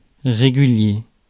Ääntäminen
IPA: [ʁe.ɡy.lje]